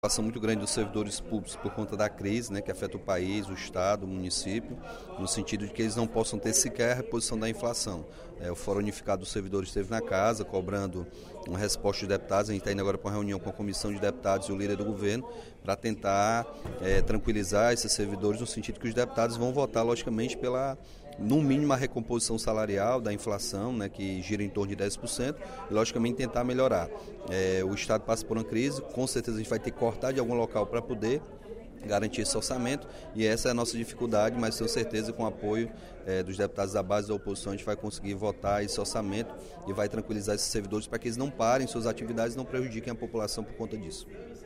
O deputado Capitão Wagner (PR) solicitou, no primeiro expediente da sessão plenária desta terça-feira (22/09), aos demais parlamentares muita cautela para a discussão e votação da Lei Orçamentária Anual (LOA), no intuito de não prejudicar os servidores públicos do Estado.